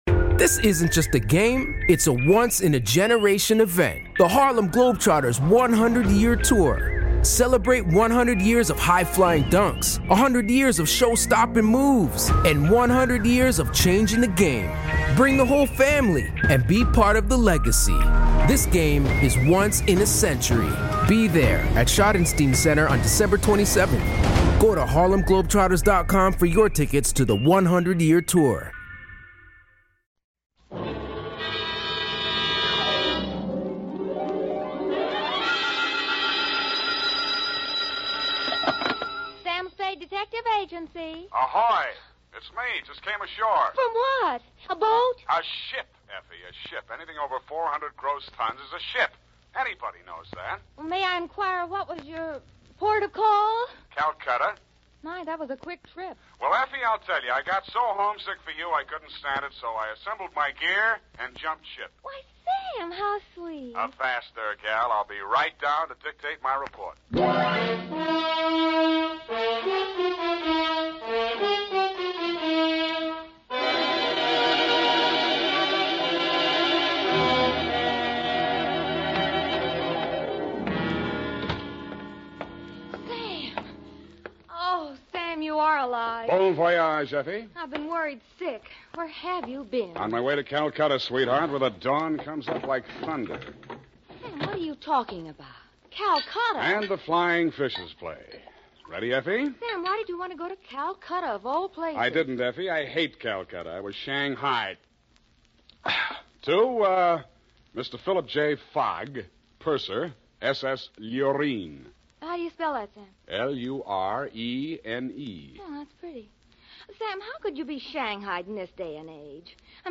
🎙 Featuring: Howard Duff as Sam Spade 🕰 Original Air Date: Circa 1948
Classic noir atmosphere — moody, witty, and fast-talking Restoration for crystal-clear sound and immersive storytelling